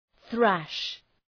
Προφορά
{ɵræʃ}